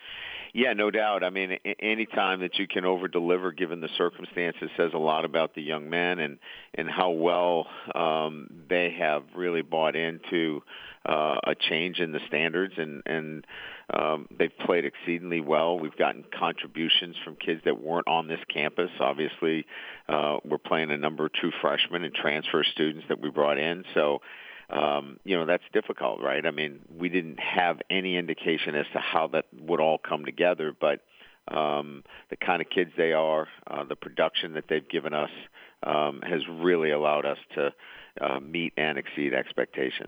LSU head coach Brian Kelly spoke at the SEC teleconference on Monday.